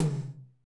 Index of /90_sSampleCDs/Northstar - Drumscapes Roland/DRM_R&B Groove/TOM_R&B Toms x
TOM R B H0GL.wav